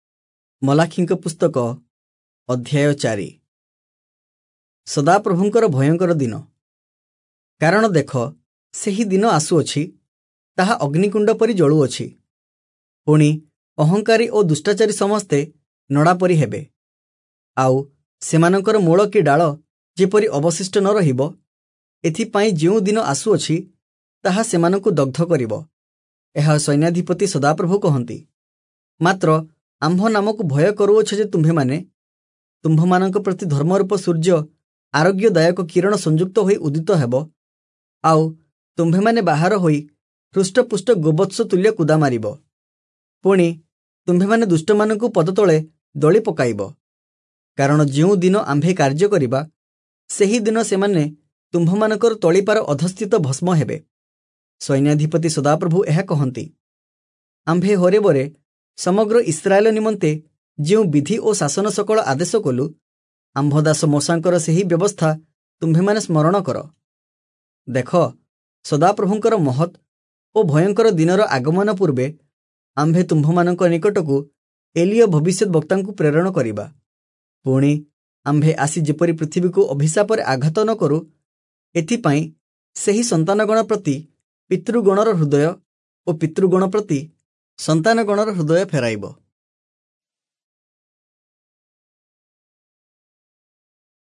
Oriya Audio Bible - Malachi All in Irvor bible version